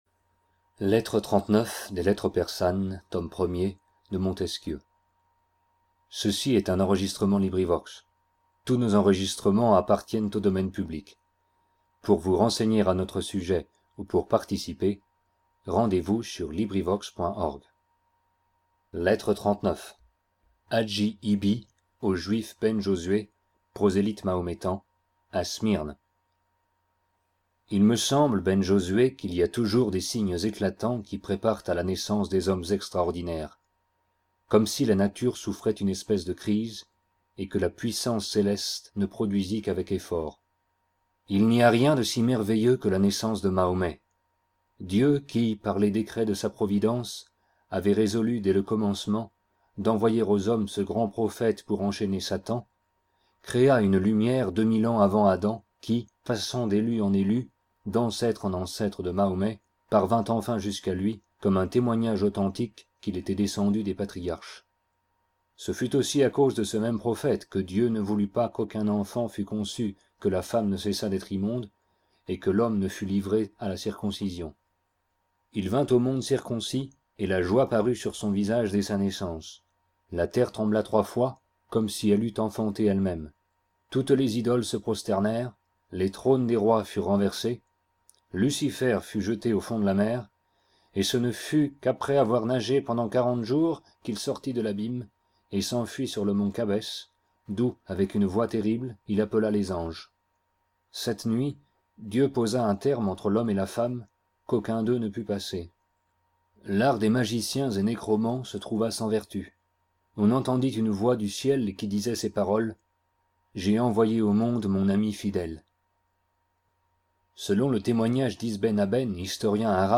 LibriVox recording by volunteers. Lettre 39. Hagi Ibbi à Ben Josué, à Smyrne .